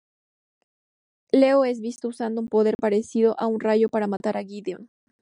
Pronounced as (IPA) /ˈleo/